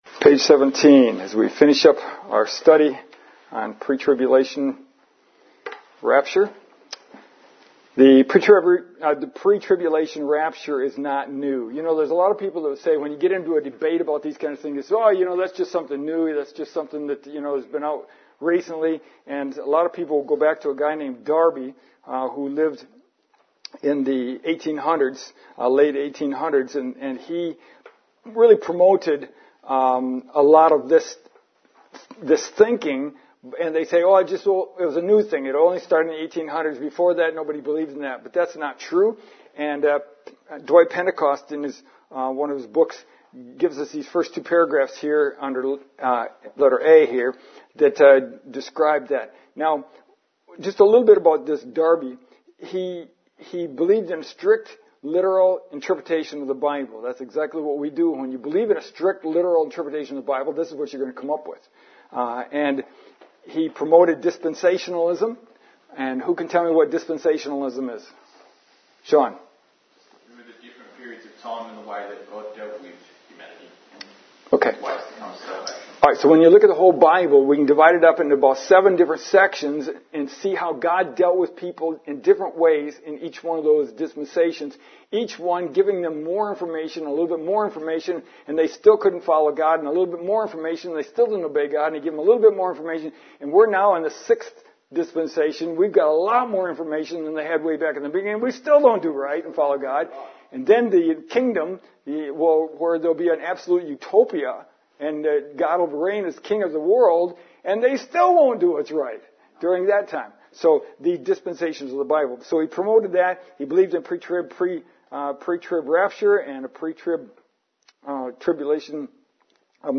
21 July 2021 Concluding lesson in this series